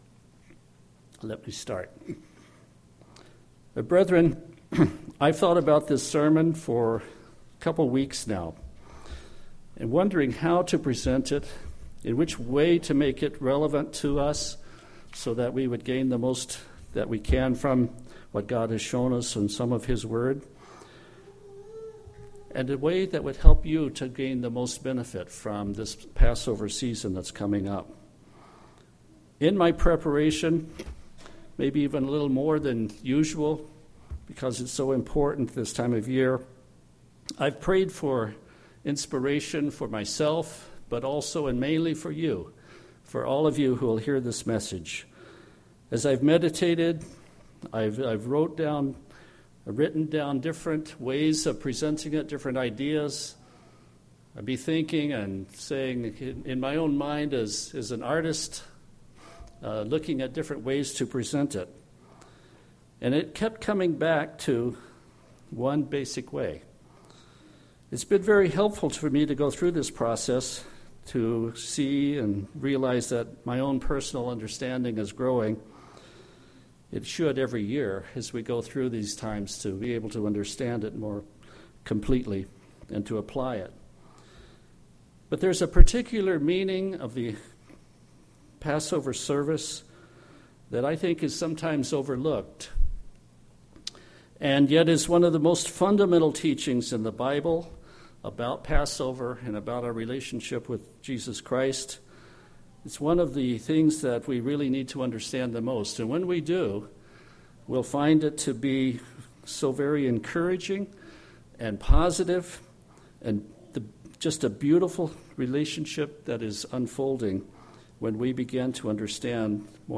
The Passover covenant is a covenant of marriage. This sermon points out the inspired verses from Scripture that reveal this wonderful truth.